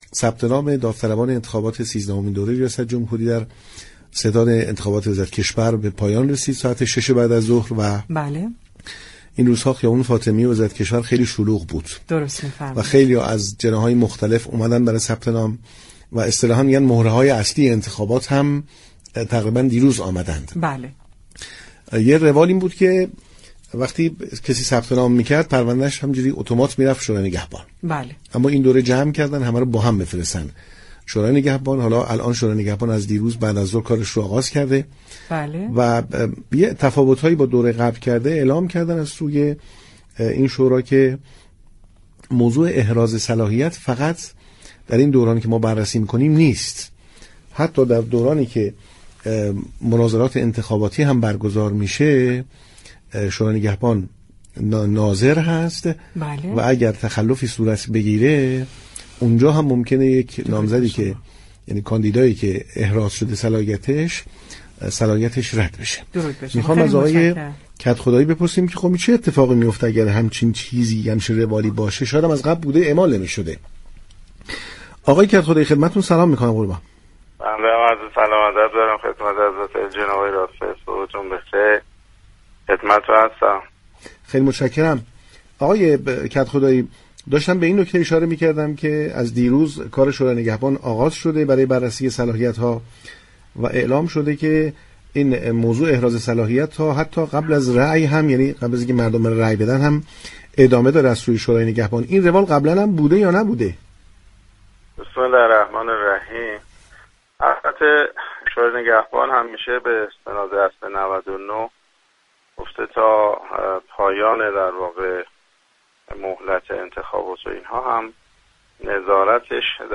در همین راستا برنامه پارك شهر 26 اردیبهشت ماه با عباسعلی كدخدایی سخنگوی شورای نگهبان گفتگو كرد.